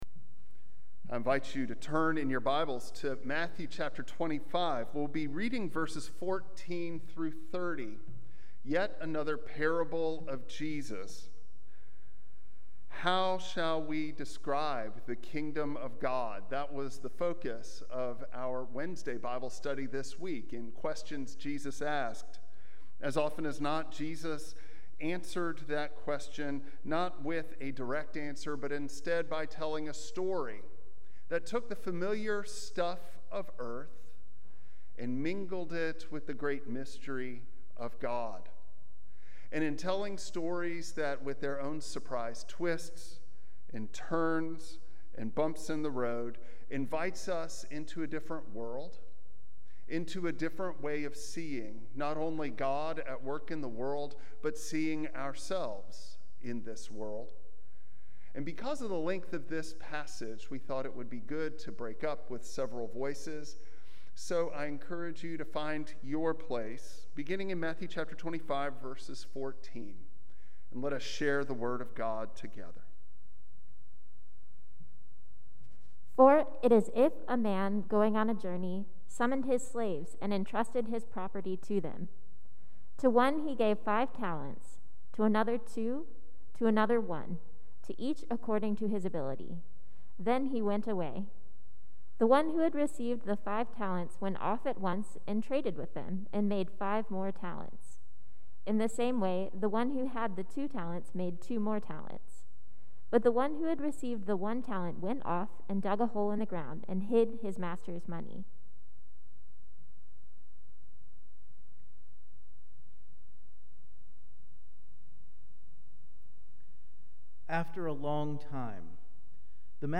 Passage: Matthew 25:14-30 Service Type: Traditional Service Bible Text